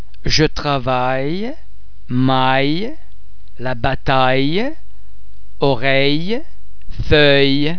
Please be mindful of the fact that all the French sounds are produced with greater facial, throat and other phonatory muscle tension than any English sound.
The French [ yeah ] sound is very much like the /y/ sound in the English words yes or eye.
·ille
ille_jetravaille.mp3